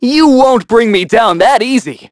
Evan-Vox_Skill1_b.wav